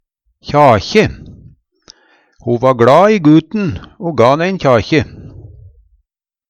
DIALEKTORD PÅ NORMERT NORSK kjakje klem Eintal ubunde Eintal bunde Fleirtal ubunde Fleirtal bunde Eksempel på bruk Ho va gLa i guten o ga'n ein kjakje.